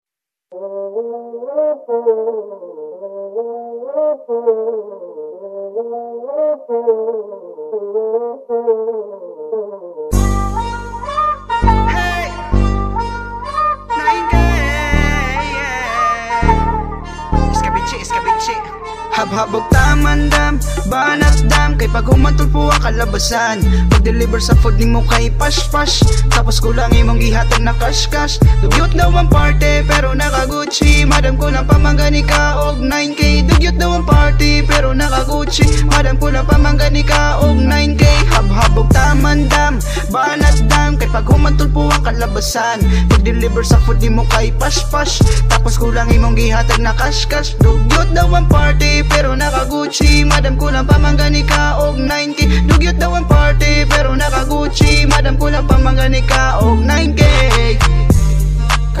Parody